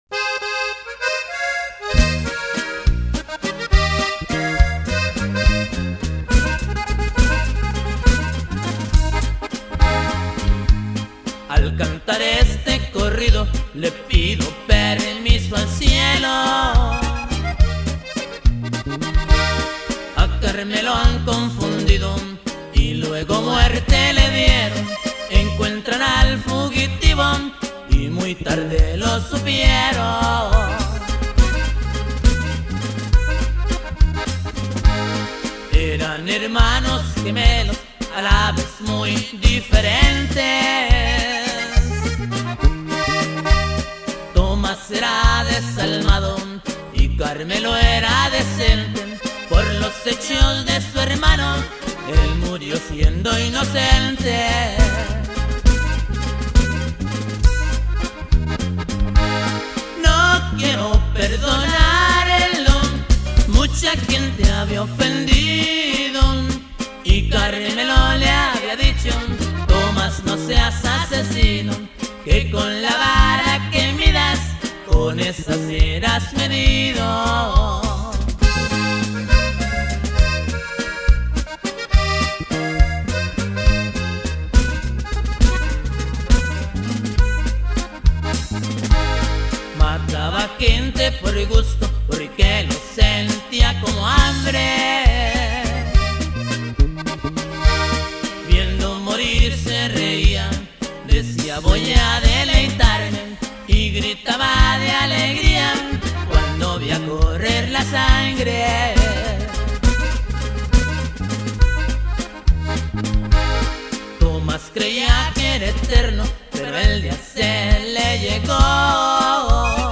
toco el bajo sexto y primera vos
1er acordion y teclados y segunda vos
bajo electrico.
segundo acordion y segunda vos.
pero el dise que es de Durango y toca la bateria.